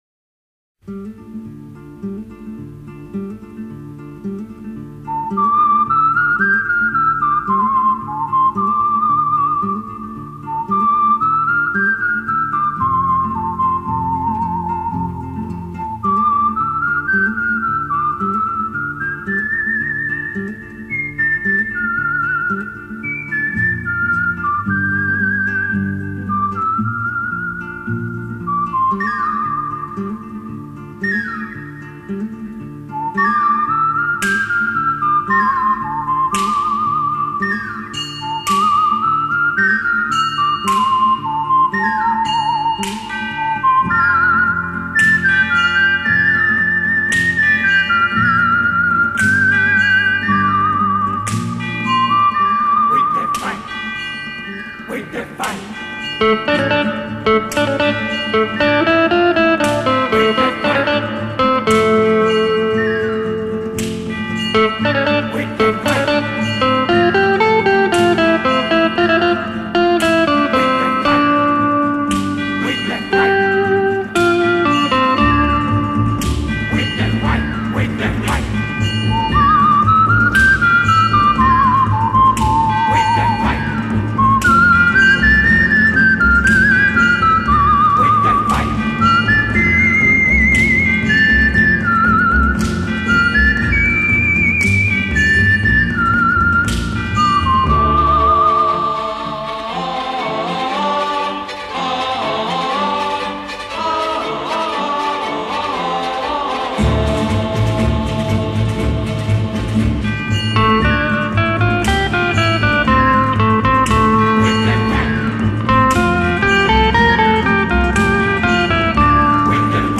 Genre:Soundtrack